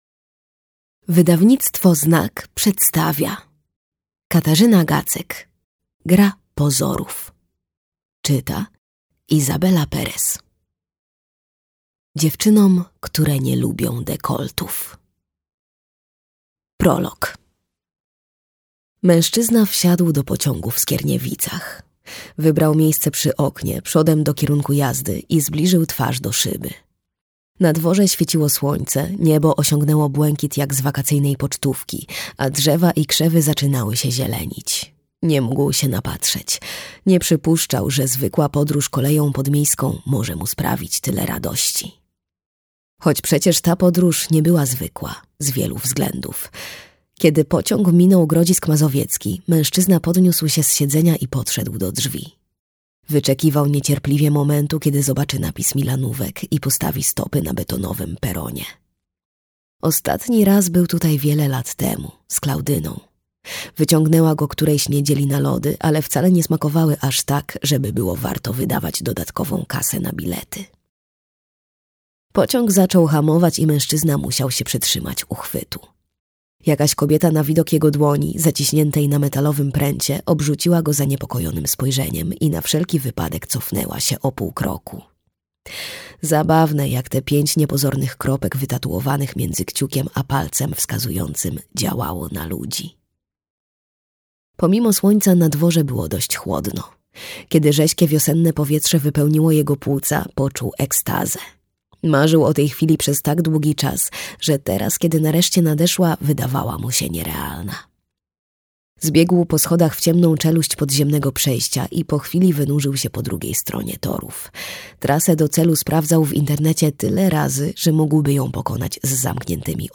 Gra pozorów - Katarzyna Gacek - audiobook